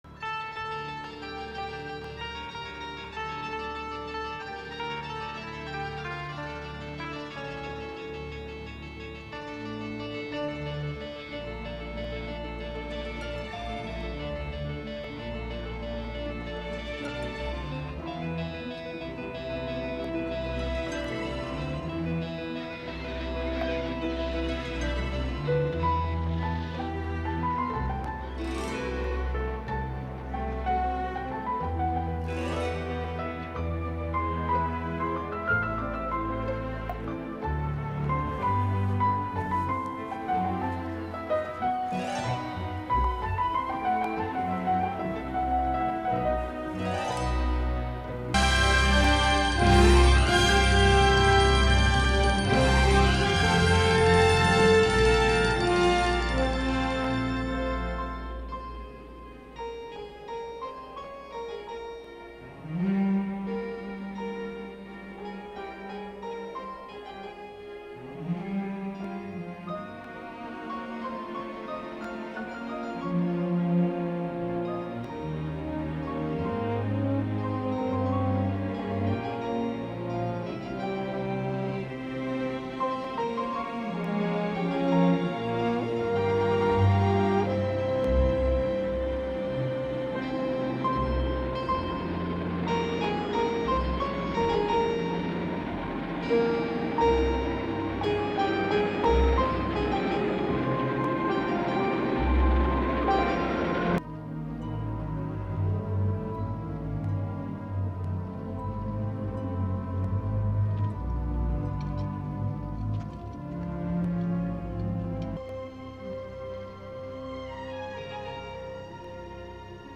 with its recurring theme is very memorable